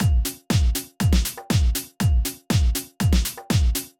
Drumloop 120bpm 02-A.wav